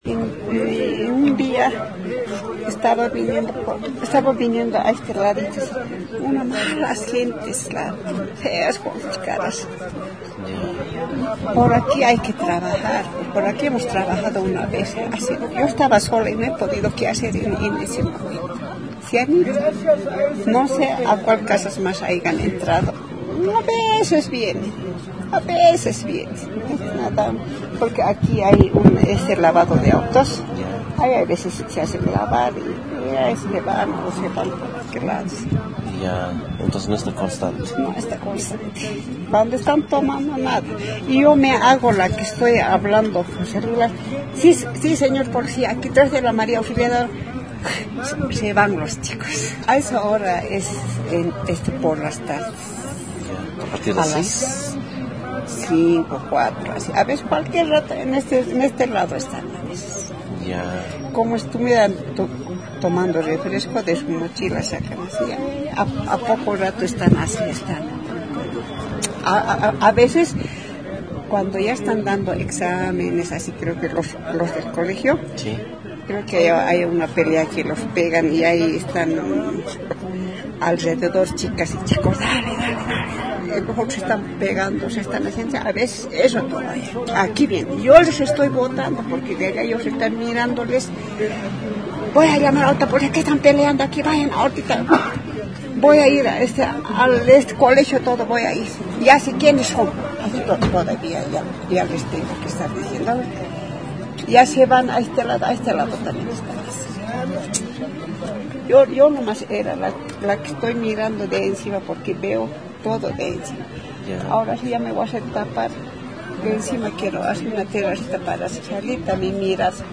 VILLA-ESPERANZA-TESTIMONIO.mp3